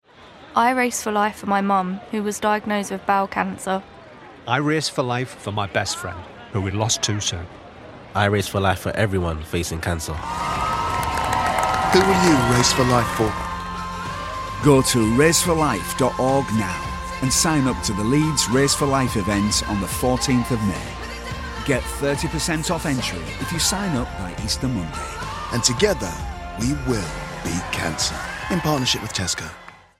Leeds / Yorkshire accent